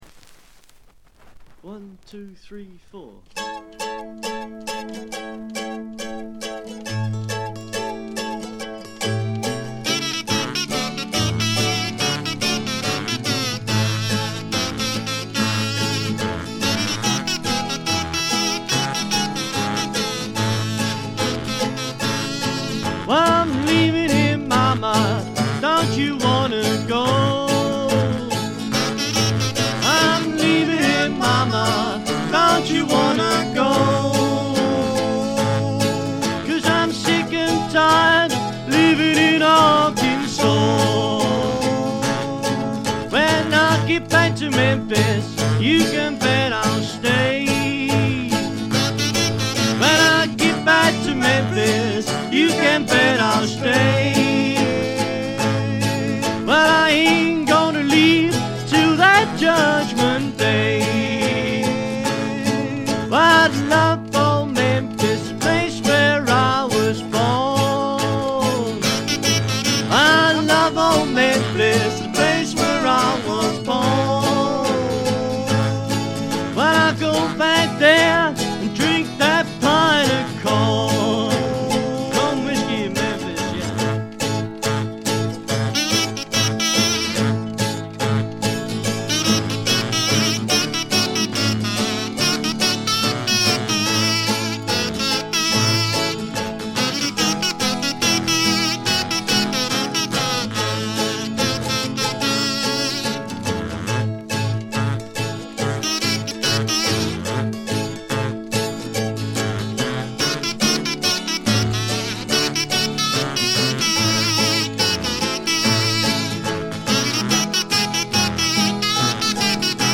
軽微なバックグラウンドノイズのみ。
試聴曲は現品からの取り込み音源です。
lead vocals, kazoo, guitar, banjo, bass drum
washboard, harmonica, jug, hoot